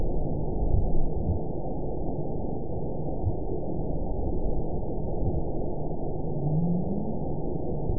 event 912304 date 03/24/22 time 02:09:10 GMT (3 years, 9 months ago) score 9.41 location TSS-AB01 detected by nrw target species NRW annotations +NRW Spectrogram: Frequency (kHz) vs. Time (s) audio not available .wav